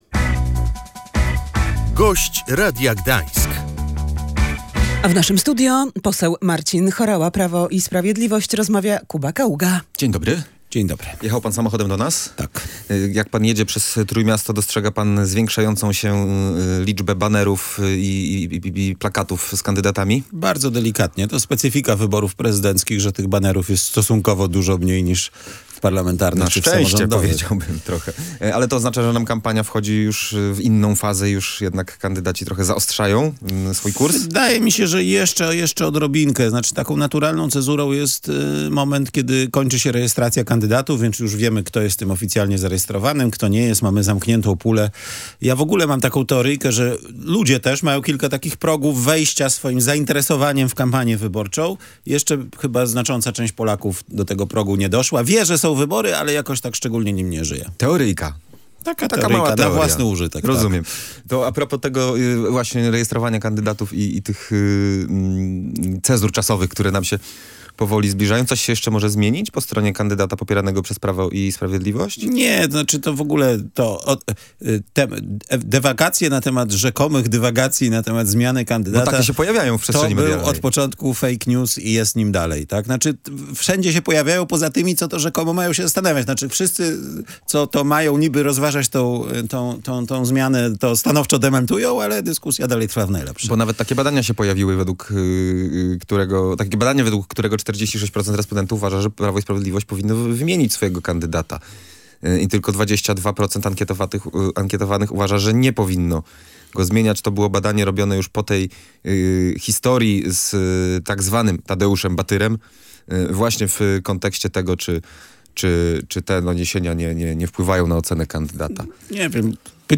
Prawo i Sprawiedliwość nie zamierza zmieniać kandydata na prezydenta Polski – zapewniał w Radiu Gdańsk poseł Marcin Horała.